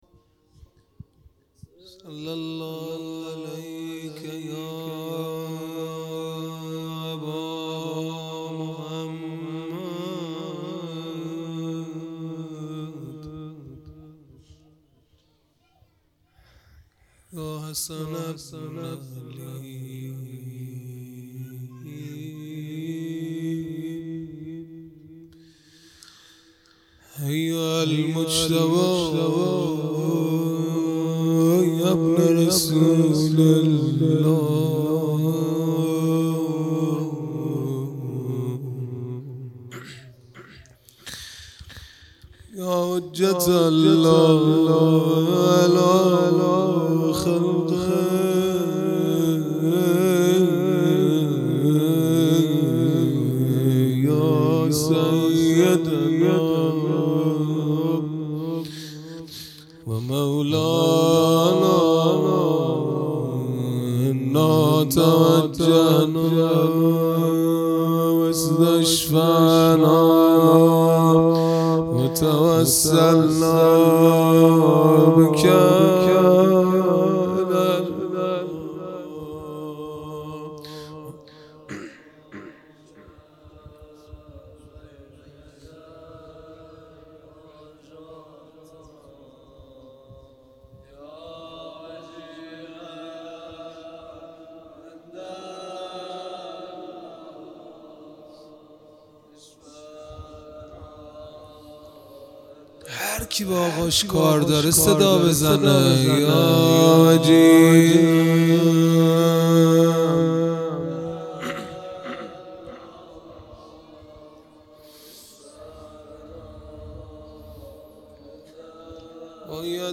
عزاداری دهه آخر صفر المظفر (شب دوم)